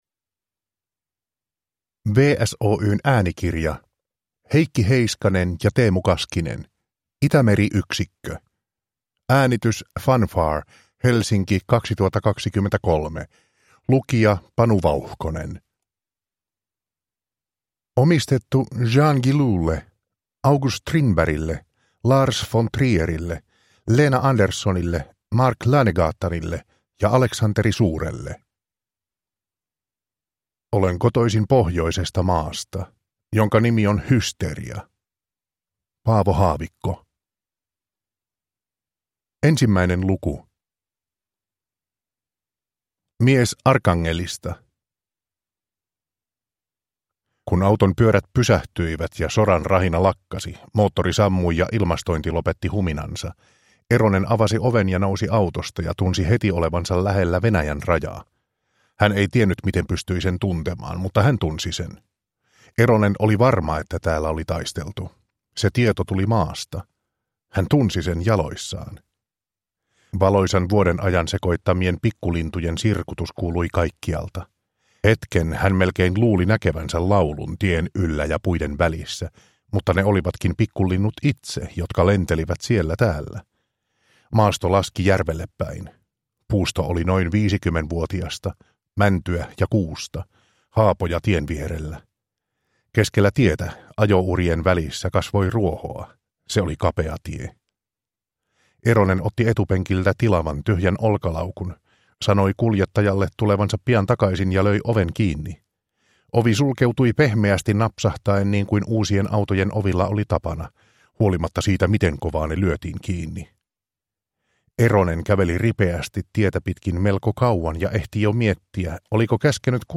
Itämeri-yksikkö – Ljudbok – Laddas ner